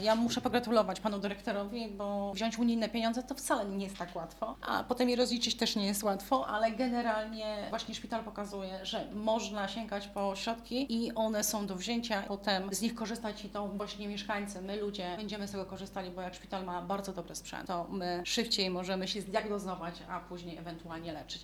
Wystąpienie Wicemarszałka Zarządu Województwa Mazowieckiego p. Janiny Orzełowskiej - plik mp3